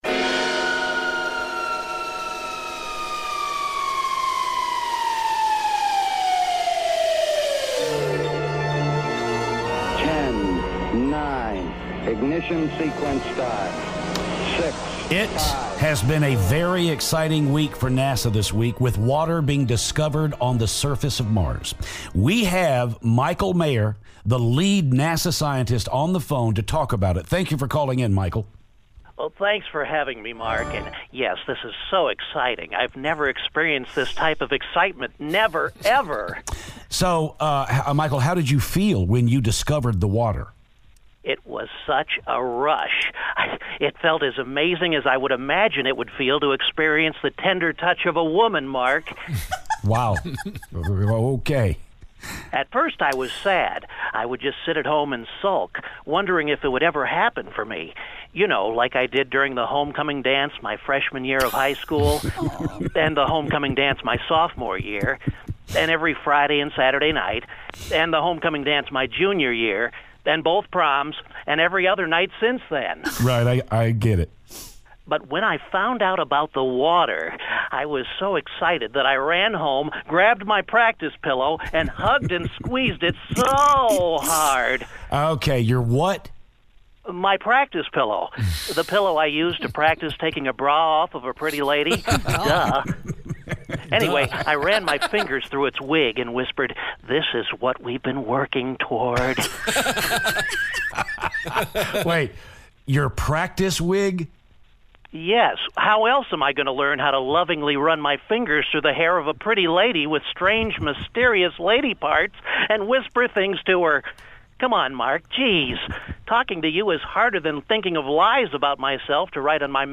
NASA Scientist Phoner